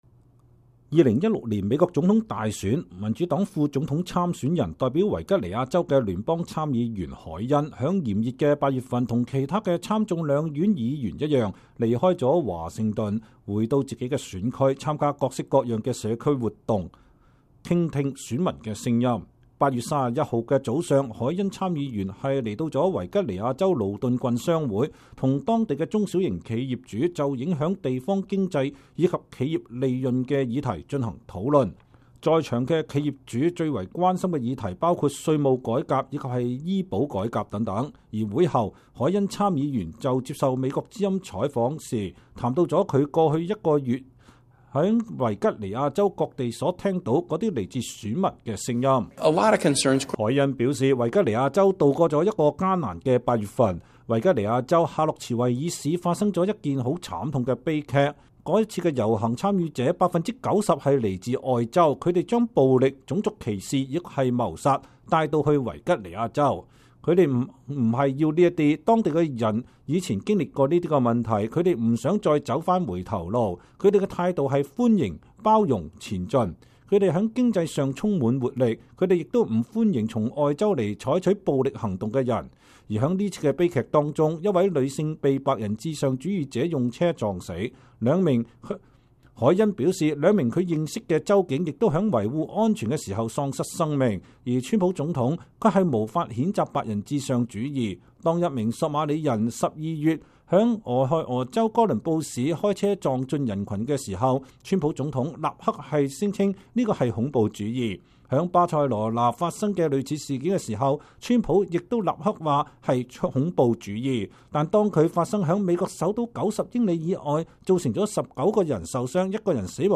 專訪前民主黨副總統參選人凱恩談朝核：軍事選項必須在檯面上